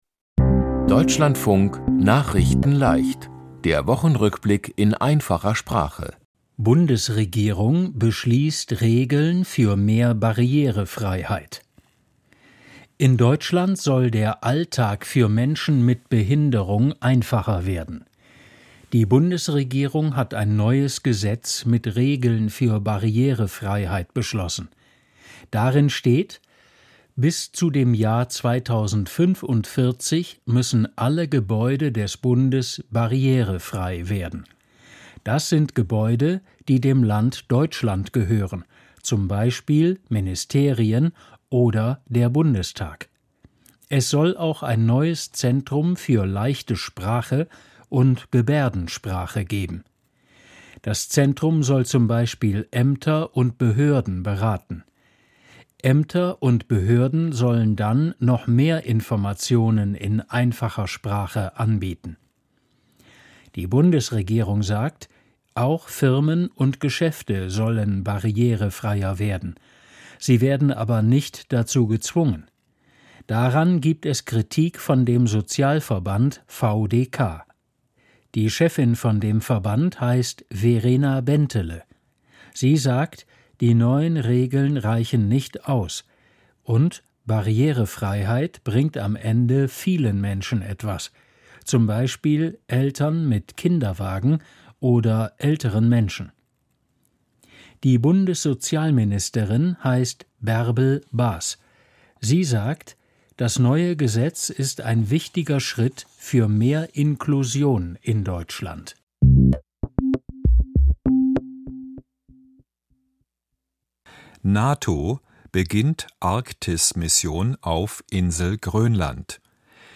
Die Themen diese Woche: Bundes-Regierung beschließt Regeln für mehr Barriere-Freiheit, NATO beginnt Arktis-Mission auf Insel Grönland, •Donald Trump ärgert sich über Super-Bowl-Show von Sänger Bad Bunny, Ukrainischer Skeleton-Sportler Heraskewytsch von Olympischen Spielen ausgeschlossen und Tausende Menschen feiern Karneval und Fastnacht. nachrichtenleicht - der Wochenrückblick in einfacher Sprache.